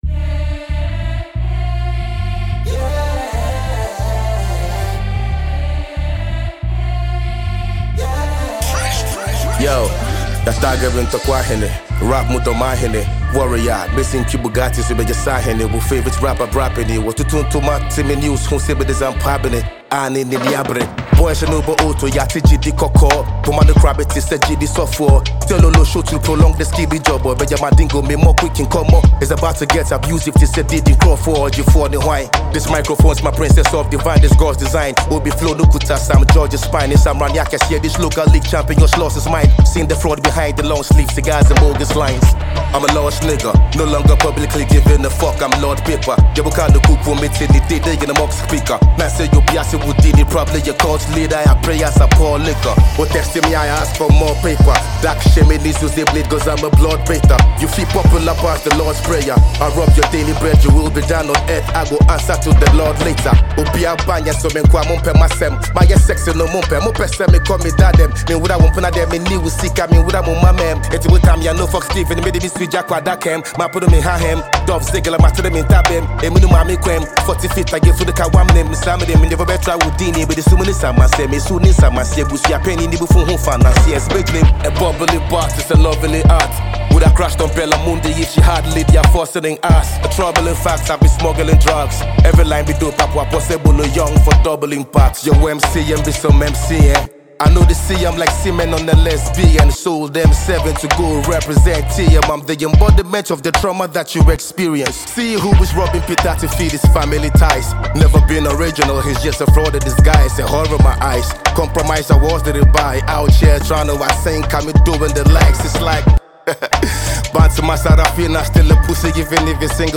is a heavyweight barfest
Ghana Music